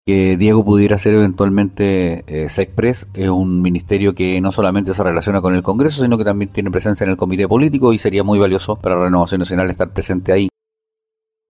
Ante este escenario, el diputado y jefe de bancada de Renovación Nacional, Frank Sauerbaum, valoró la figura de Paulsen como eventual ministro.